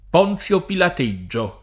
pilateggiare v.; pilateggio [pilat%JJo], -gi — fut. pilateggerò [pilateJJer0+] — voce rara per «lavarsene le mani (come Ponzio Pilato)» — anche ponziopilateggiare: ponziopilateggio [